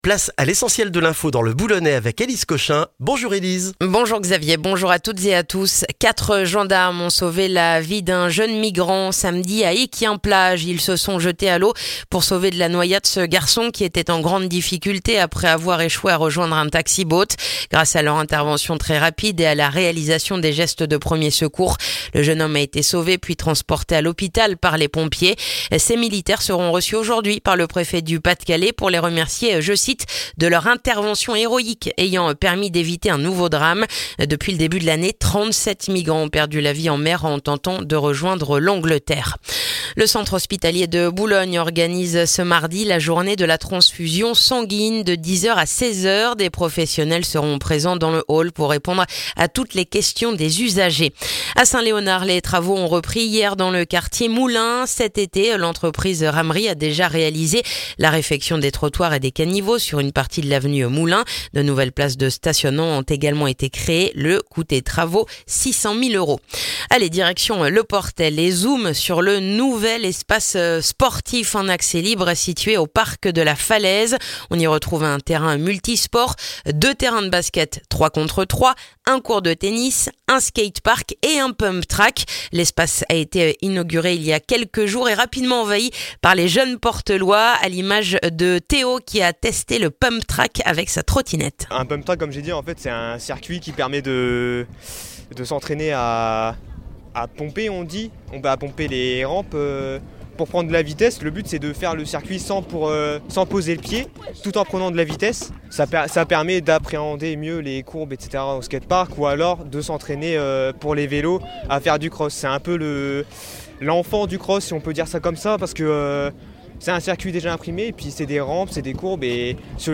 Le journal du mardi 10 septembre dans le boulonnais